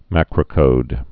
(măkrə-kōd)